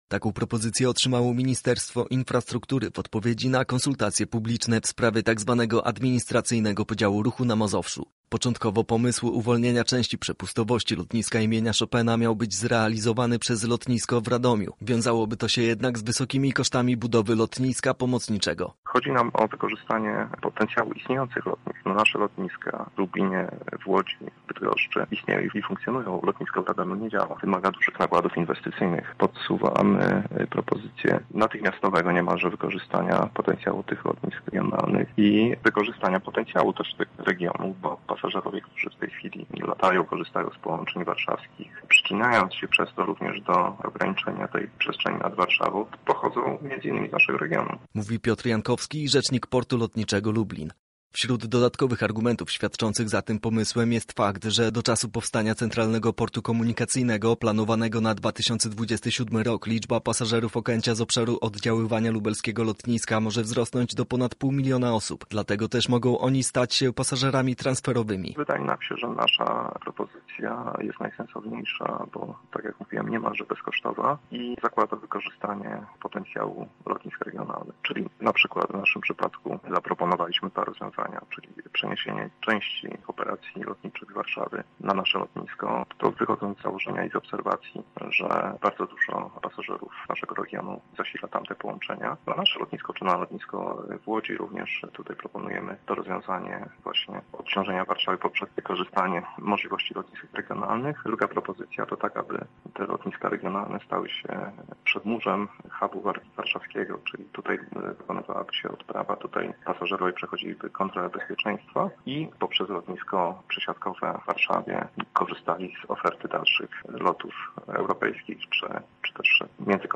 Więcej w materiale naszego reportera: